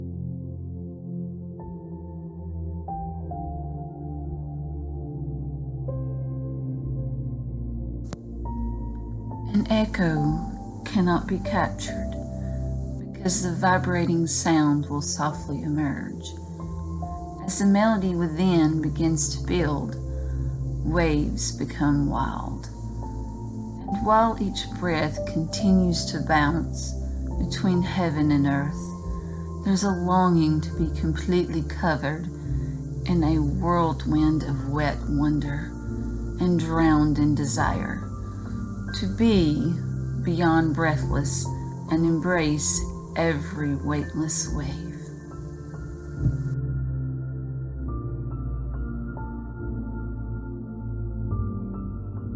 This beautiful, sensual scribble is even beautiful hearing you recite your words my sweet friend.